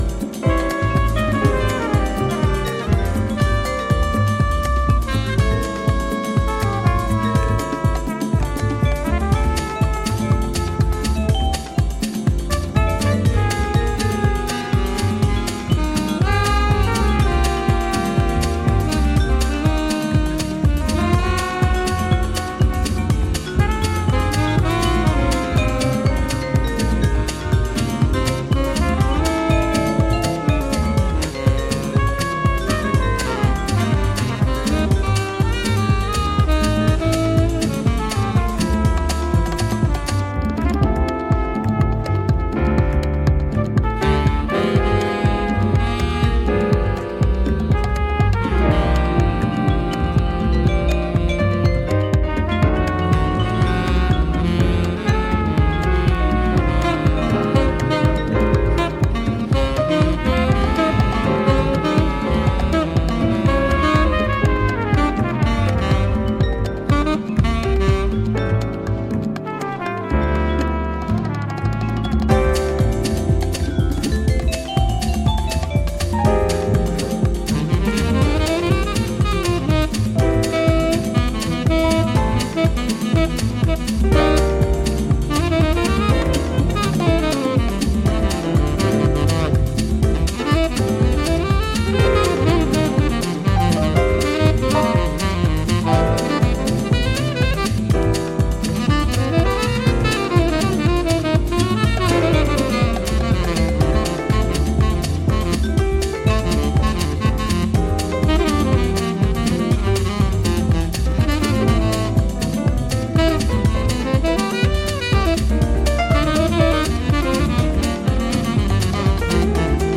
ハウス、ジャズ、ブレイクビーツがブレンドされたおすすめ盤です！